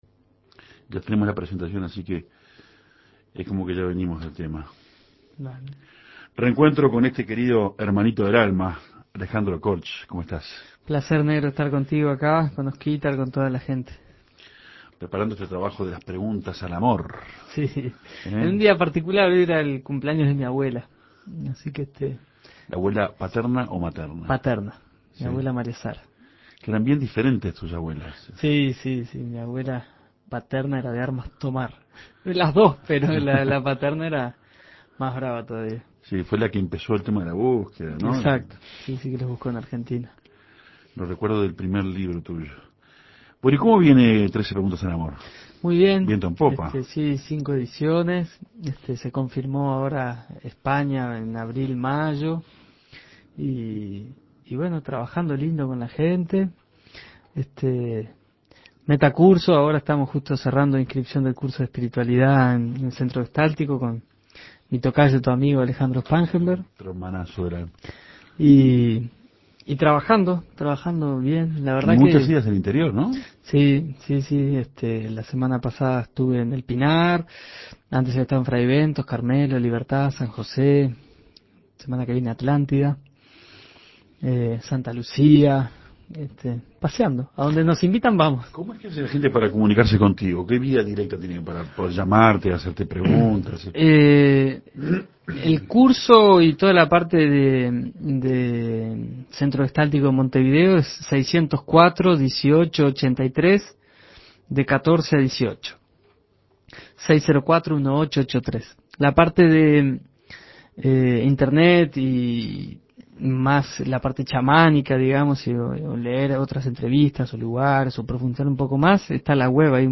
"¿Qué es la pareja?" fue la elegida para esta ocasión. Escuche la entrevista.